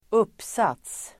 Uttal: [²'up:sat:s]